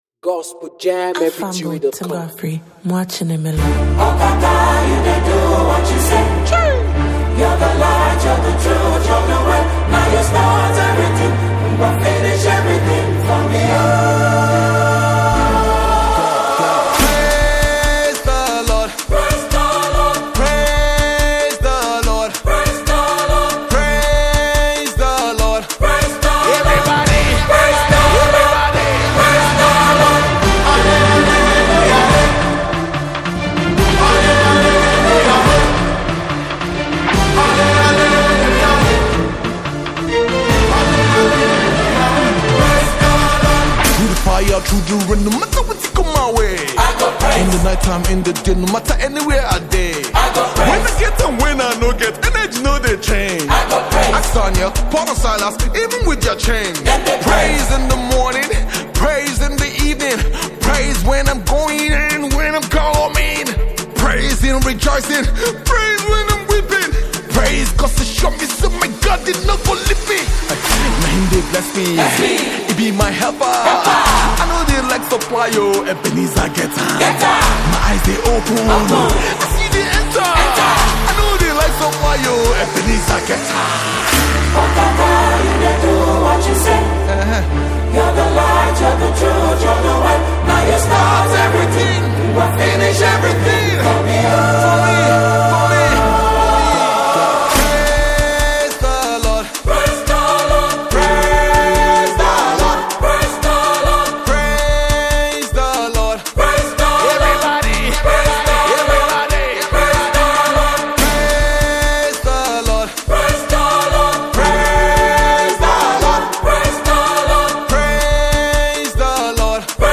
vibrant gospel anthem
🎵 Style: Afro-gospel / Contemporary praise